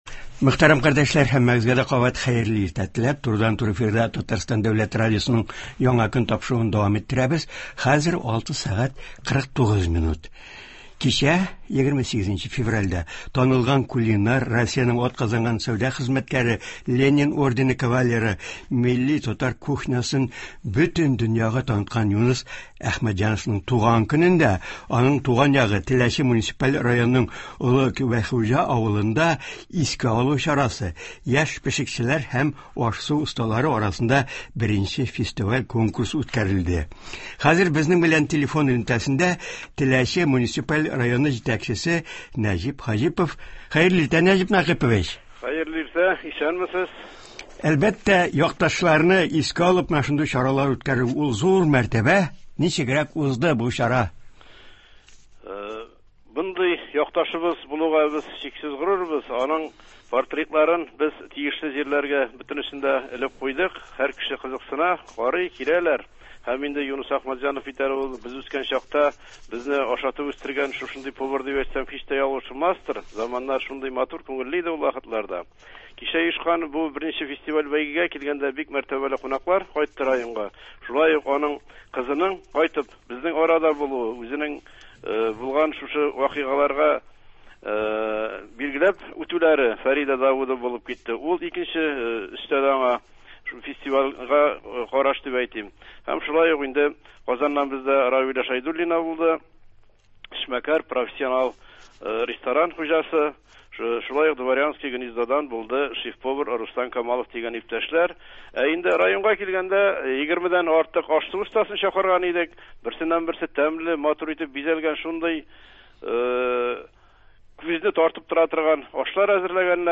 Болар хакында турыдан-туры эфирда Татарстан авыл хуҗалыгы һәм азык-төлек министрының терлекчелек буенча урынбасары Ленар Гарипов сөйләячәк, тыңлаучылардан килгән сорауларга җавап бирәчәк.